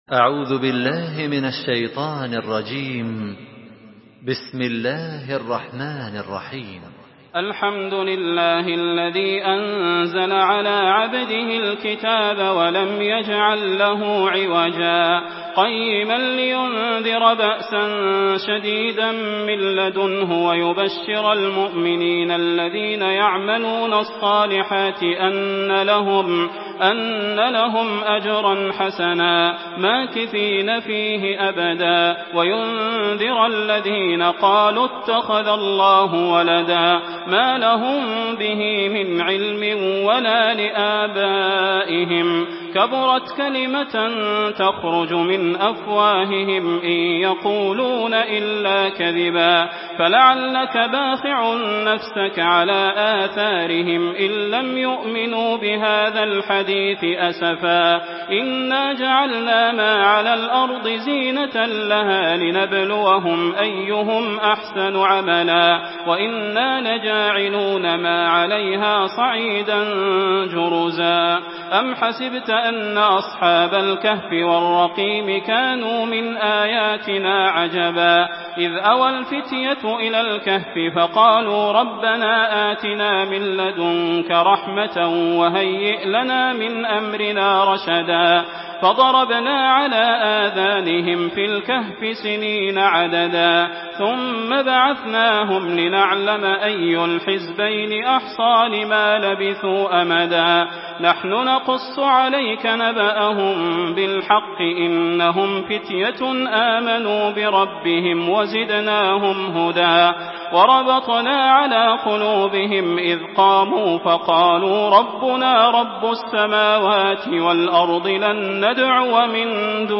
Surah Al-Kahf MP3 by Makkah Taraweeh 1427 in Hafs An Asim narration.
Murattal